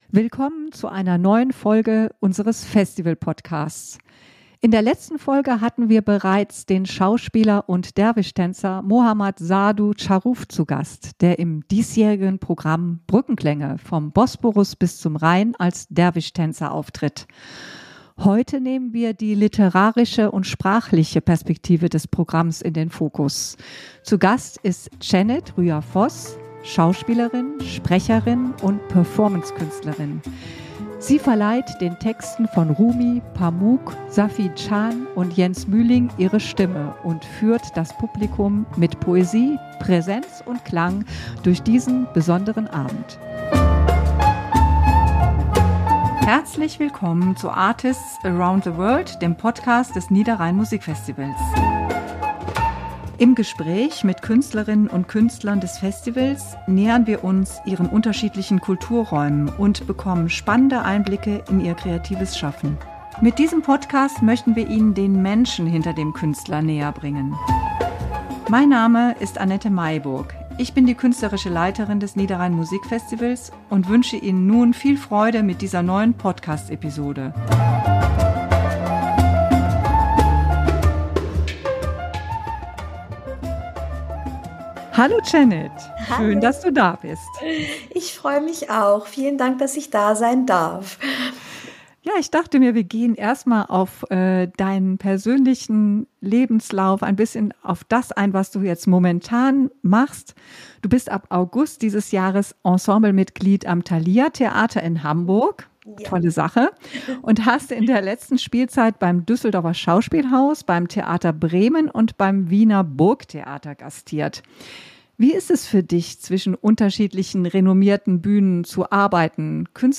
017 Es gibt einen gemeinsamen Atem | Interview